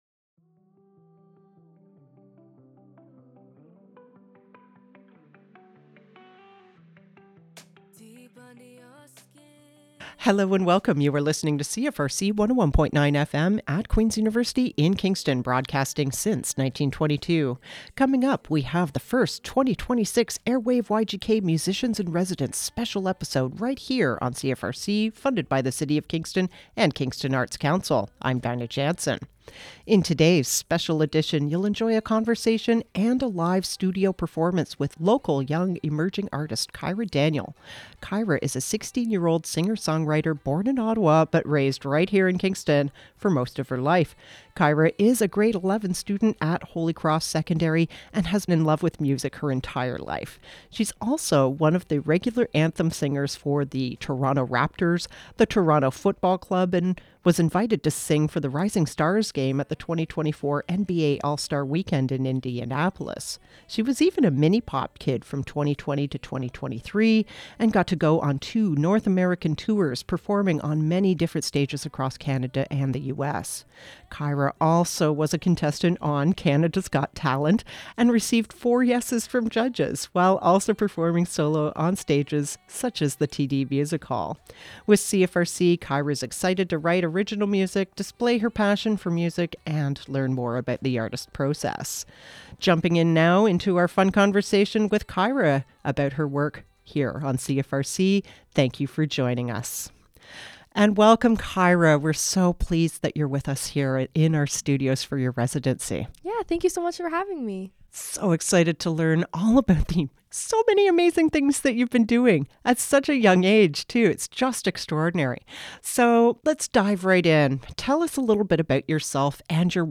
Welcome to a fresh new season of CFRC’s Airwave YGK programming!